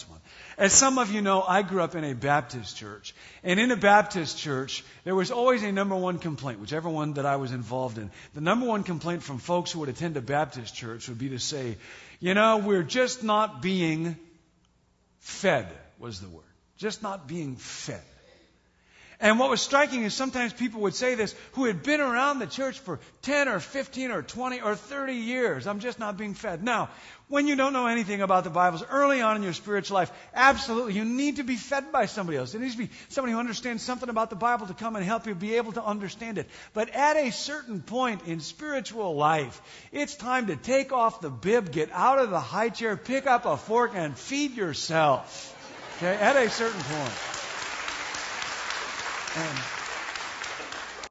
Audio clip — A message titled, “Next Steps: The Gap,” by John Ortberg)